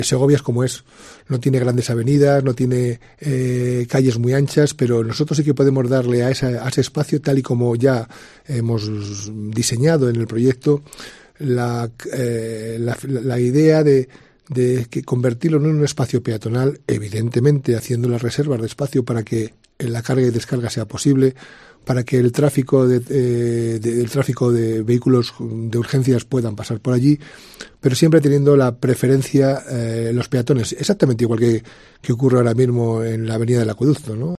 José Mazarías, alcalde de Segovia, sobre la calle Blanca de Silos